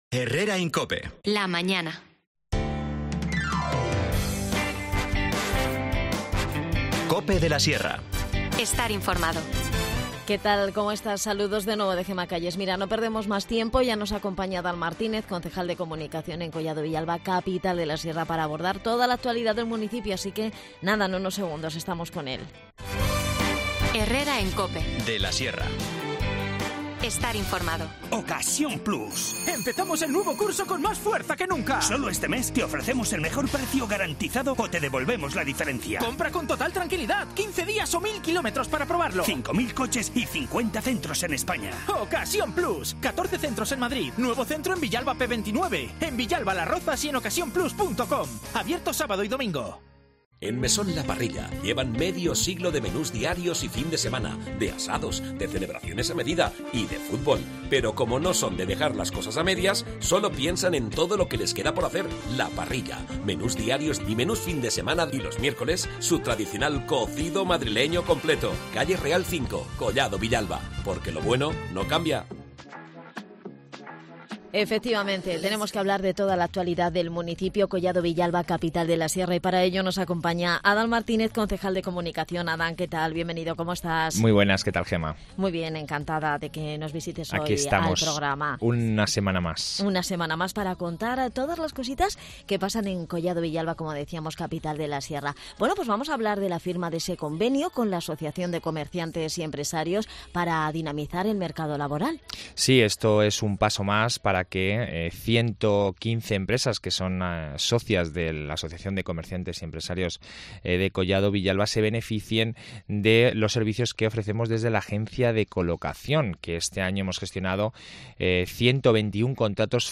Nos visita Adan Martínez, concejal de Comunicación en Collado Villalba, Capital de la Sierra, para hablarnos de toda la actualidad del municipio que pasa entre otros asuntos por recordar la firma de un convenio con la Asociación de Comerciantes y Empresarios para dinamizar el mercado laboral. Además, el edil nos habla de otras cuestiones relacionadas con el empleo, la justicia, la solidaridad, el ocio en familia y la gastronomía.